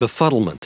Prononciation du mot befuddlement en anglais (fichier audio)